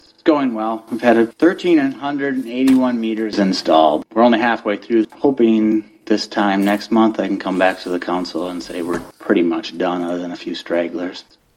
Soundbite: